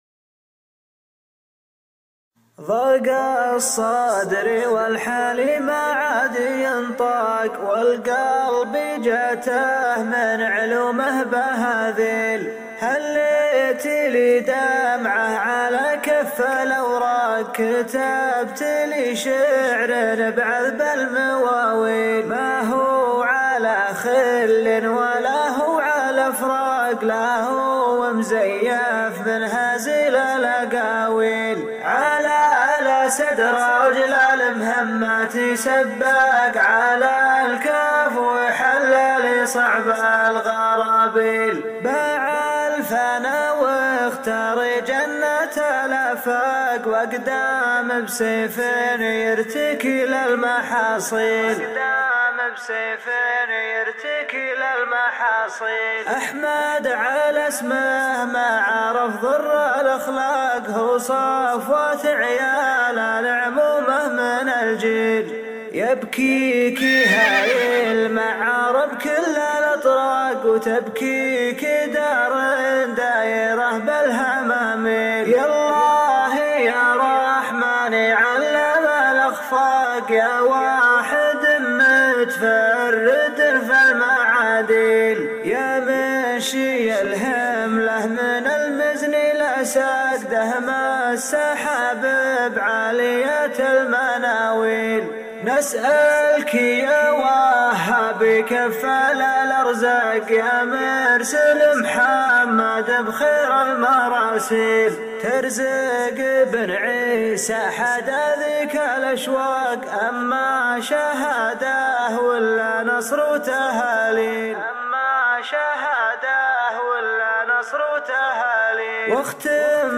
مسرع